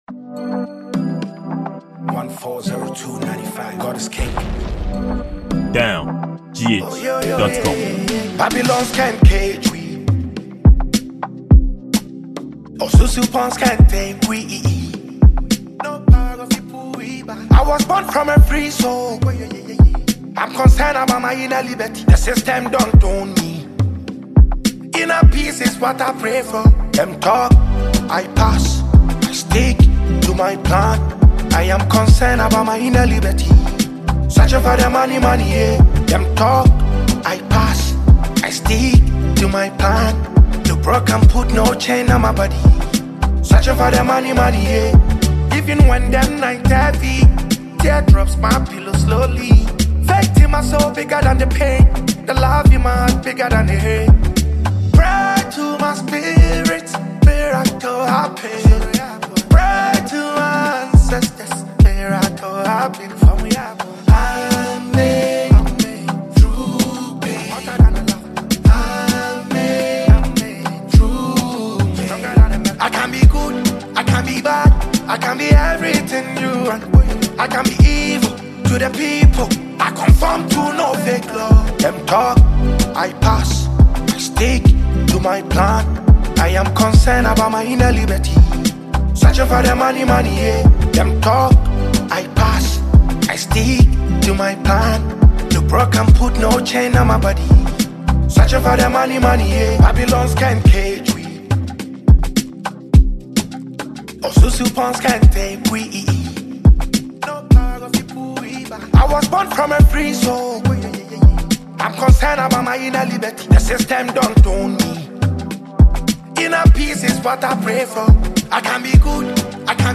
Genre: Highlife